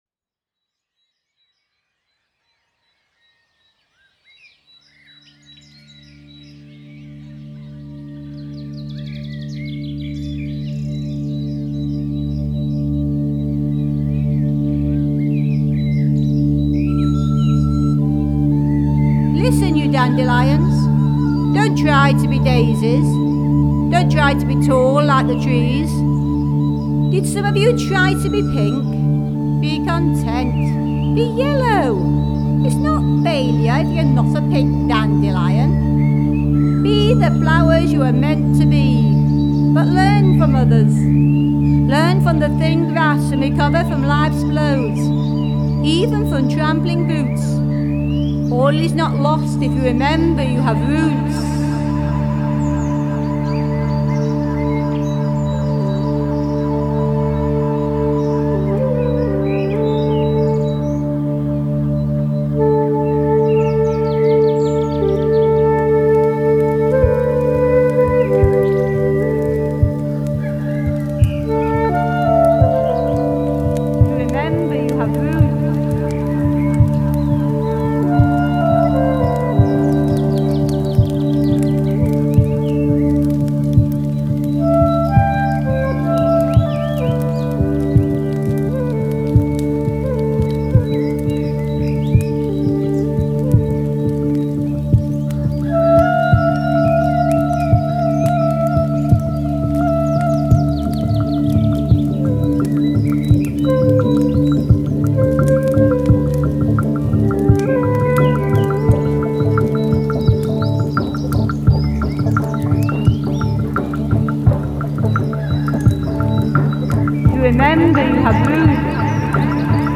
~ Soundscapes and voices for the larger than human community ~ Soundscapes and voices for the larger than human community MP3